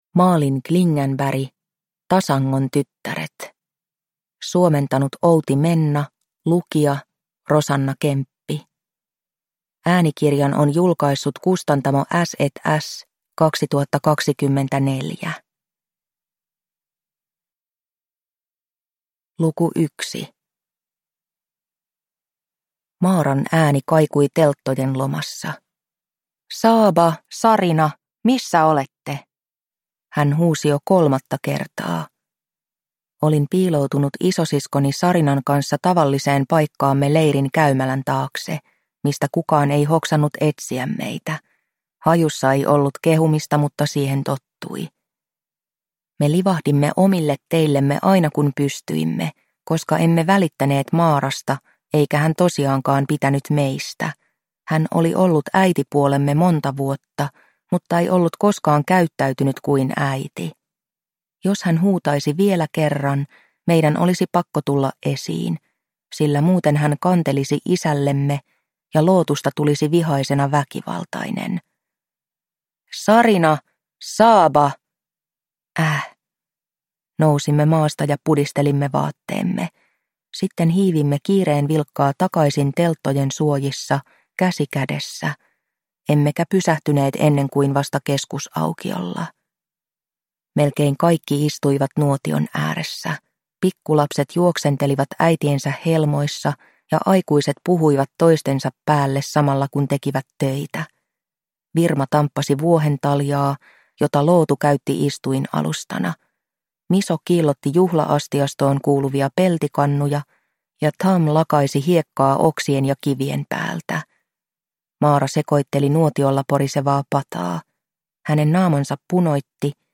Tasangon tyttäret – Ljudbok